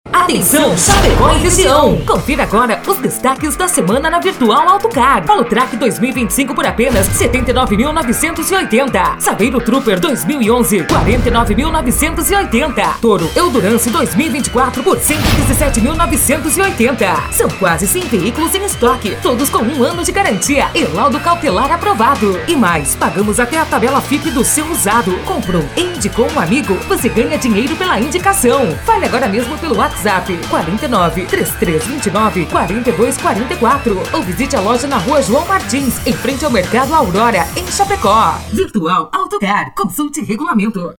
impacto :